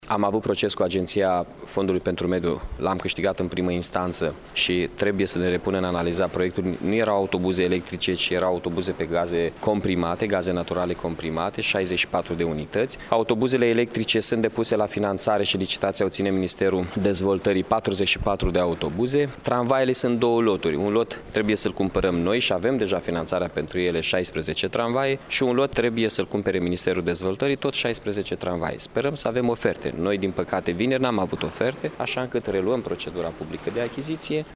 Primarul Mihai Chirica a declarat că nu renunţă la ideea de a achiziţiona 64 de astfel de autobuze: